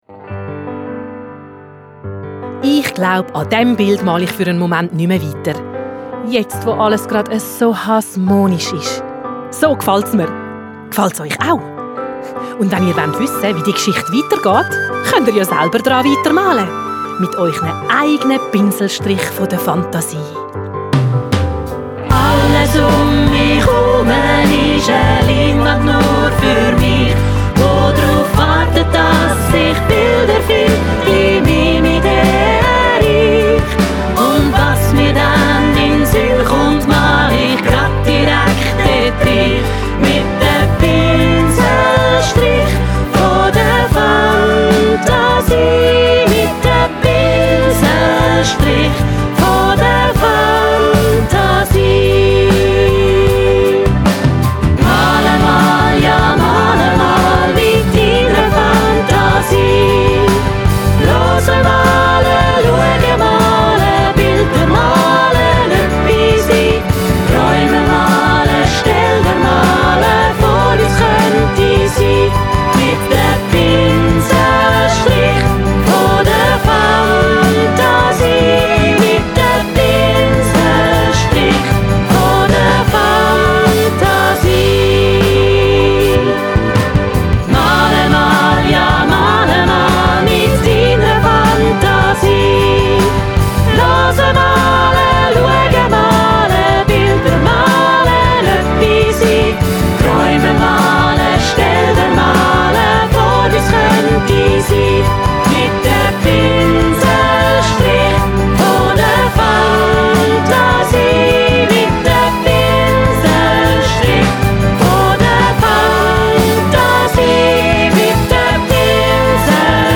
Familien-Musical